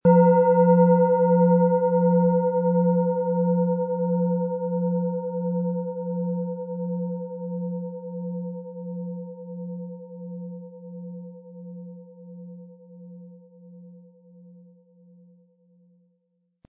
Planetenschale® Ins Fließen kommen & Alle Regionen mit einer Schale ansprechen mit Wasser-Ton, Ø 13 cm inkl. Klöppel
SchalenformBihar
MaterialBronze